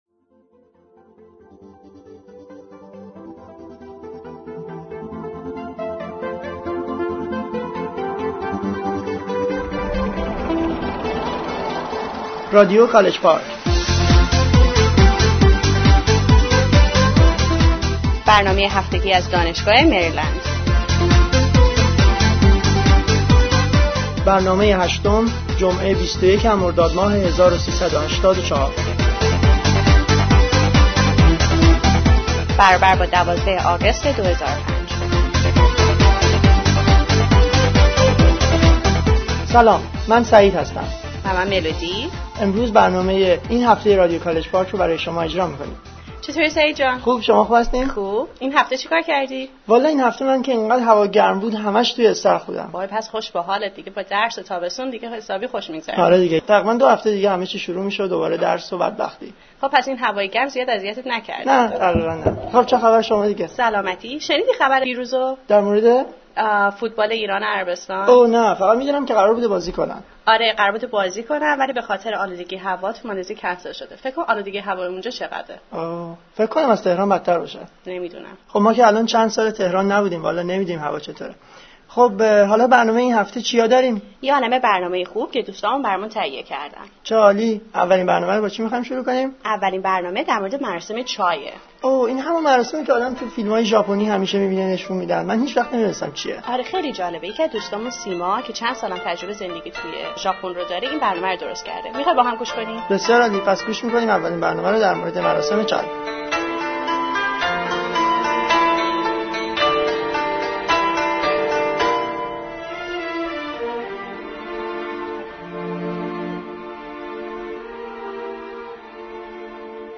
An Interview with One of the Ph.D. Graduates of the University of Maryland